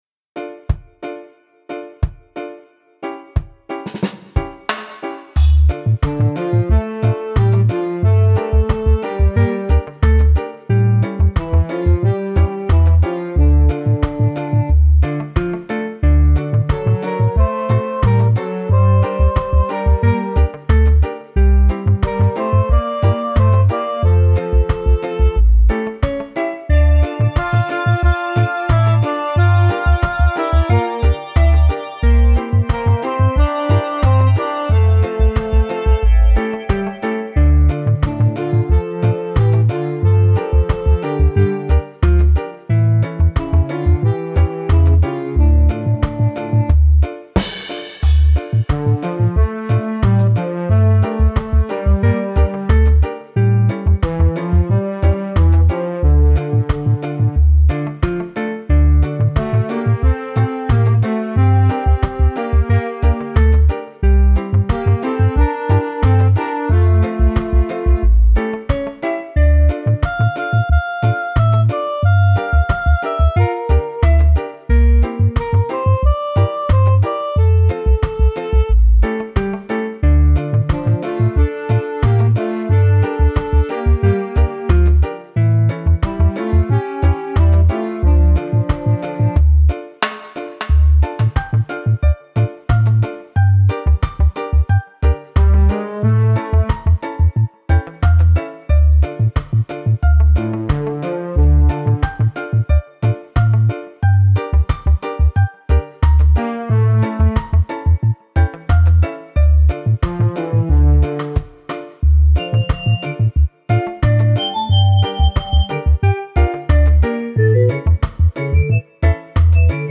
Level 7 (Be careful of volume.).